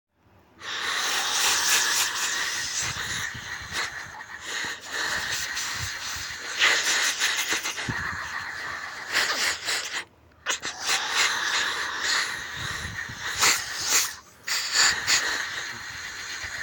Sonido-aspiradora.mp3
MJFrj0yQknw_Sonido-aspiradora.mp3